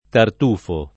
tartufo [ tart 2 fo ]